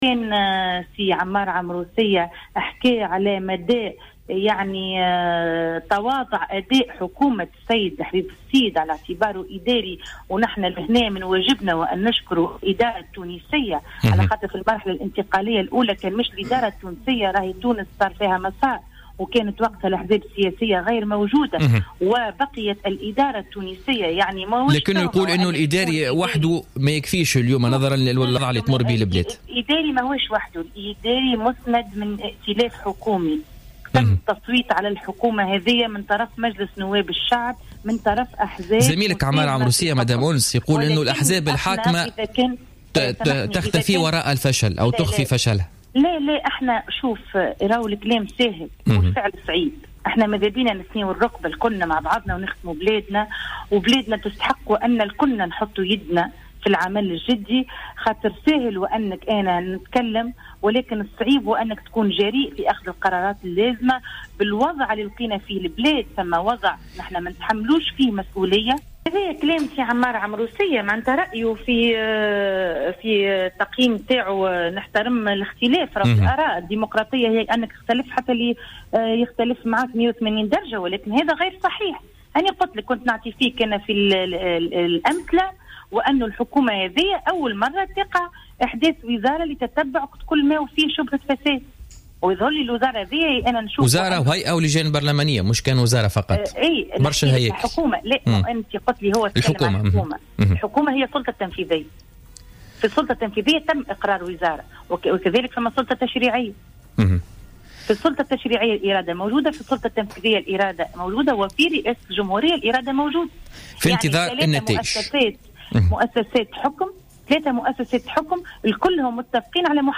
ردت أنس الحطاب النائب عن حزب حركة نداء تونس وعضو في لجنة الإصلاح الإداري والحوكمة الرشيدة ومكافحة الفساد ومراقبة التصرف في المال العام في تصريح للجوهرة أف أم في برنامج بوليتكا لليوم الاثنين 18 أفريل 2016 على انتقادات النائب عن الجبهة الشعبية عمار عمروسية لأداء حكومة الحبيب الصيد وافتقارها للإرادة والجرأة لمحاربة الفساد".